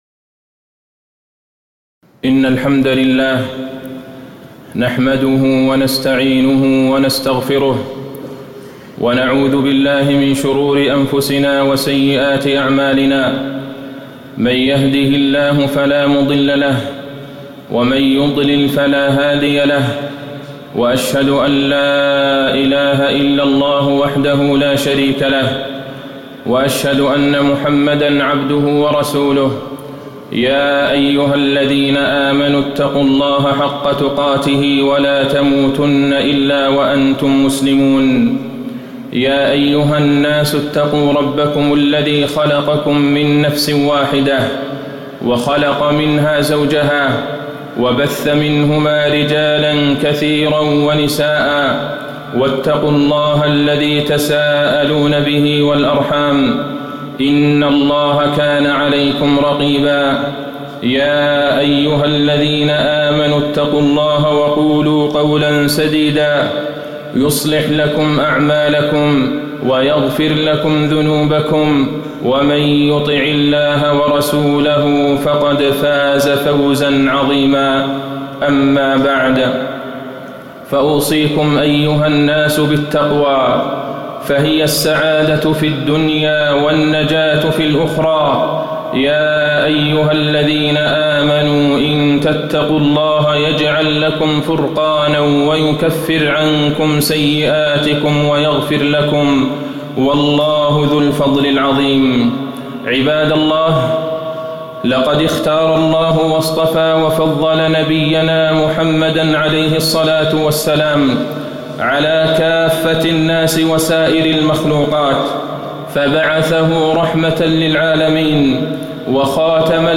خطبة محبة النبي صلى الله عليه وسلم وفيها: اصطفاء الله لنبيه في كل شيء، واقتران طاعة النبي بطاعته سبحانه، وأن محبة النبي أصل إيماني، ومن دلائل محبته
تاريخ النشر ٤ صفر ١٤٣٨ المكان: المسجد النبوي الشيخ: فضيلة الشيخ د. عبدالله بن عبدالرحمن البعيجان فضيلة الشيخ د. عبدالله بن عبدالرحمن البعيجان محبة النبي صلى الله عليه وسلم